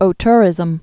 (ō-tûrĭzəm)